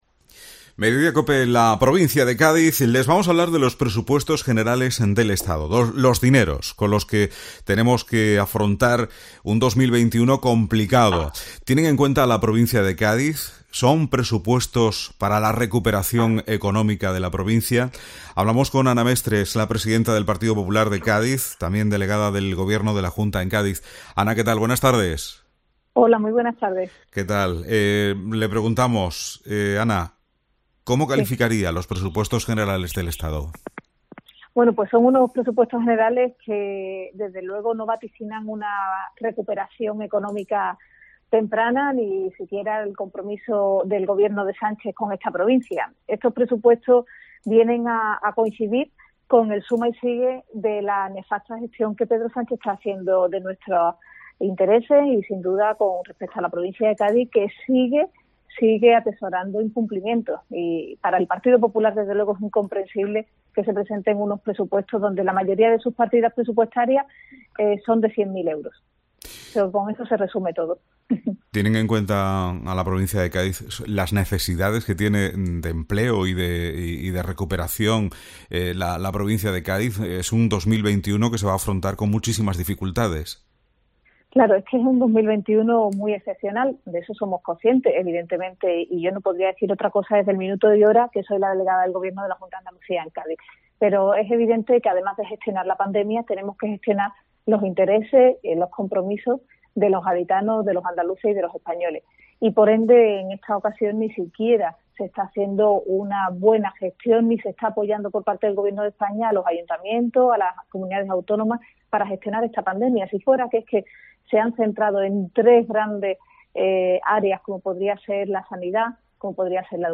Ana Mestre analiza los PGE en los micrófonos de COPE Cádiz